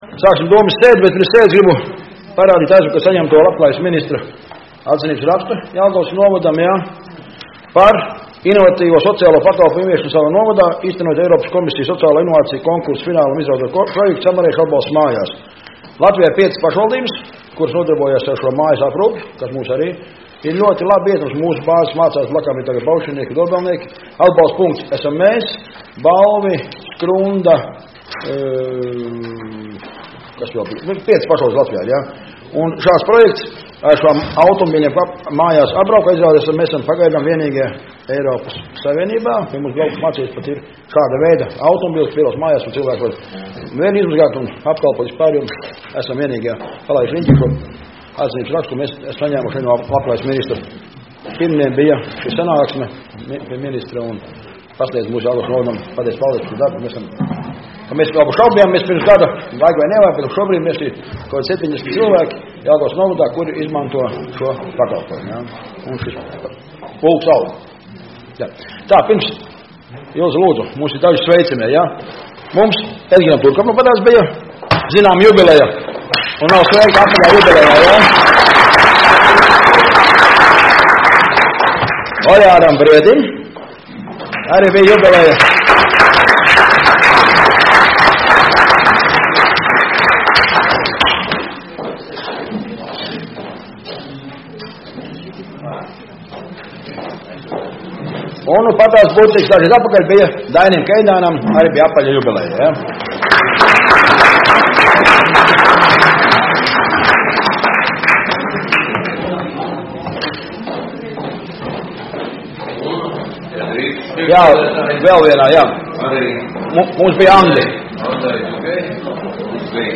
Domes sēde Nr. 20